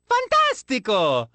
"Fantastico!" One of Mario's voice clips from the Awards Ceremony in Mario Kart: Double Dash!!
MKDD_Mario_Fantastico_Voice_Clip.oga.mp3